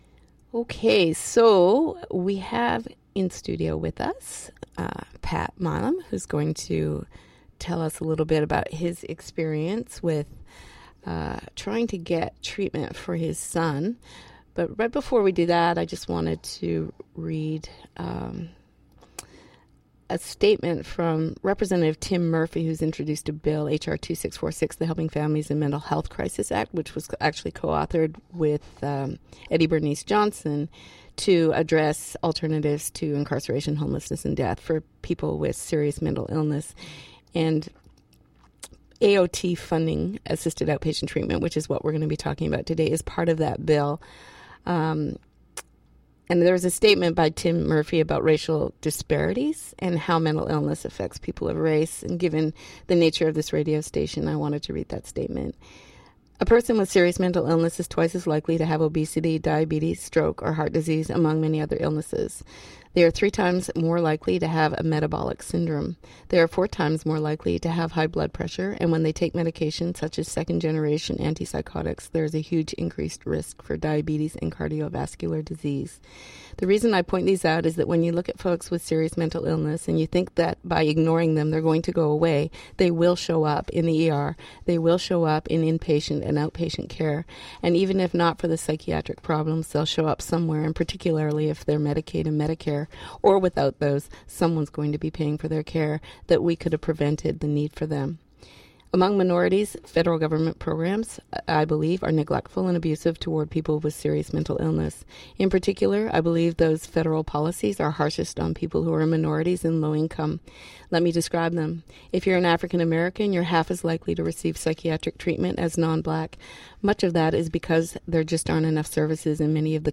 interviews Judge Calvin Johnson about the Assisted Outpatient Treatment law and two parents in the New Orleans region who share their stories about similar struggles to get care for their seriously mentally ill children that resulted in very different outcomes. Discussed is a little known law that has been implemented in almost every State in the Union known as Assisted Outpatient Treatment. In Louisiana the law is known as Nicola's law. It has been proven successful in providing families with the tools they need to care for seriously mentally ill loved ones in the community who may be intentionally or unintentionally resisting care.